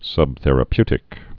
(sŭbthĕr-ə-pytĭk)